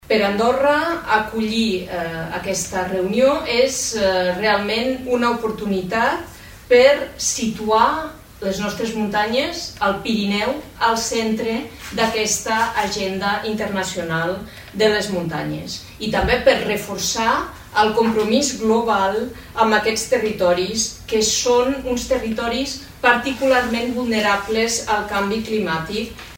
La ministra d’Afers Exteriors, Imma Tor, ha destacat que acollir aquesta reunió és una oportunitat per situar les muntanyes del Pirineu al centre del debat internacional.